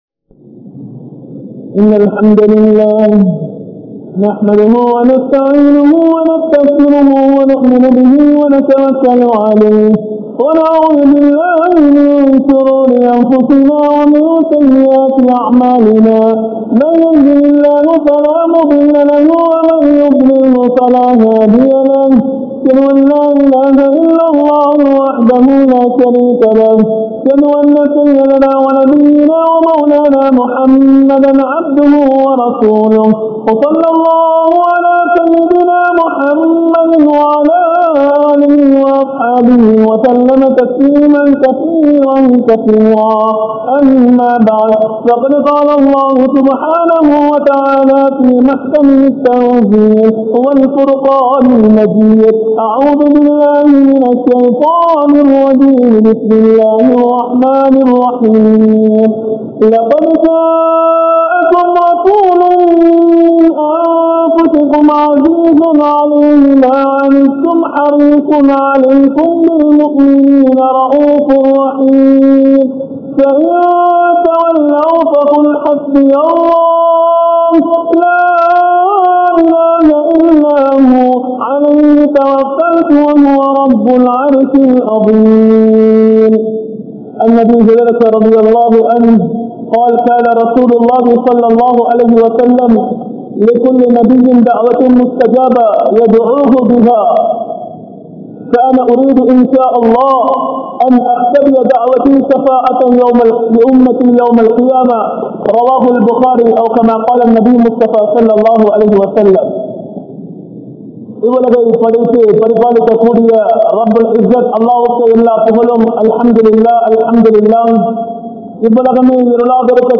Nabi(SAW)Avarhalin Pirappu Muthal Irappu Varai (நபி(ஸல்)அவர்களின் பிறப்பு முதல் இறப்பு வரை) | Audio Bayans | All Ceylon Muslim Youth Community | Addalaichenai
Colombo 12, Aluthkade, Muhiyadeen Jumua Masjidh